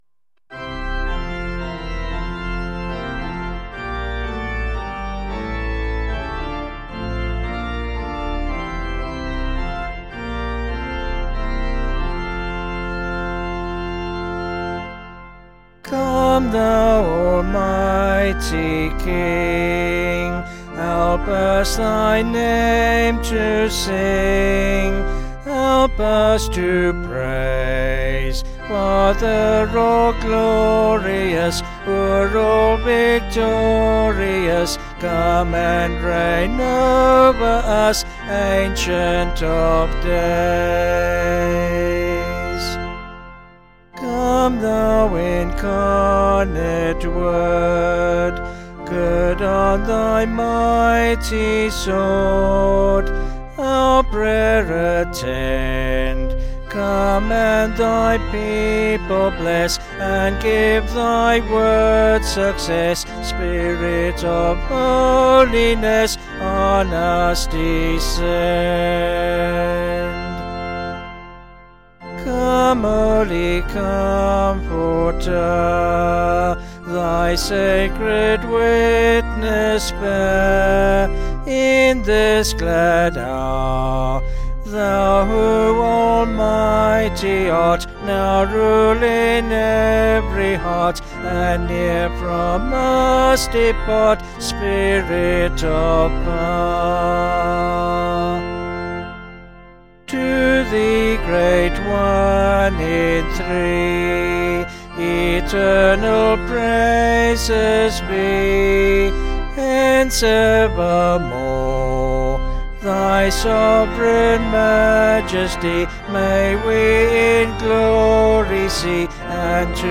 Vocals and Organ   704.2kb Sung Lyrics